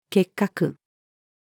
結核-female.mp3